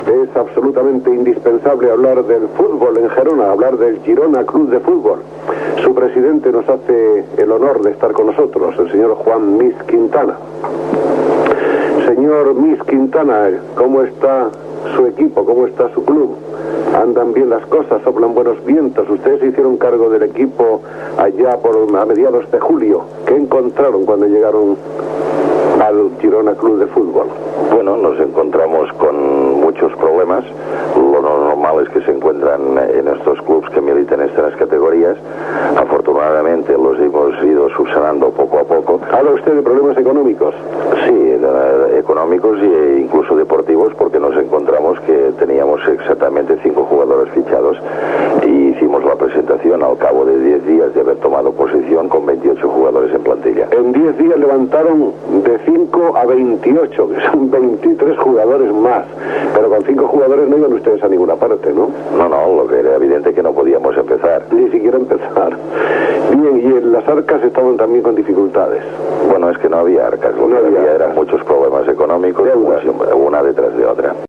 Entrevista
Espai que feia un recorregut per Catalunya en un vagó de tren convertit en estudi de ràdio, l'estiu de 1984.